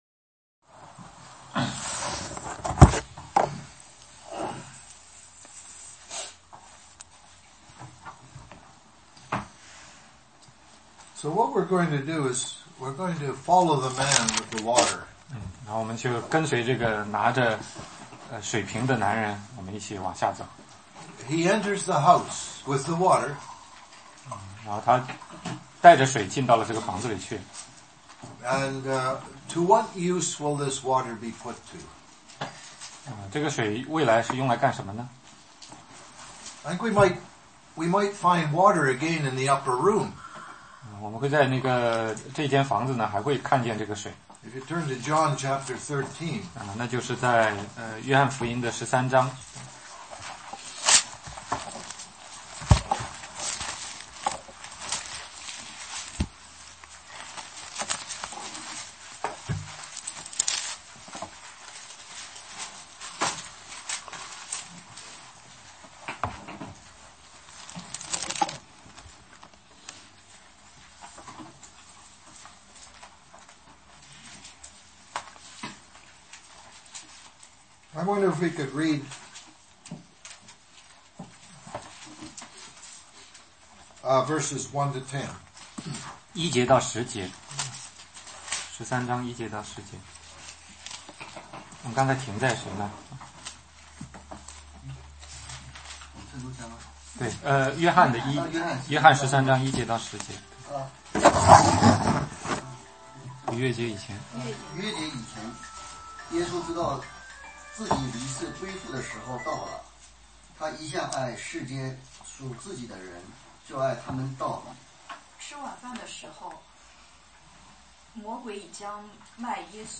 16街讲道录音 - 路加22-7,10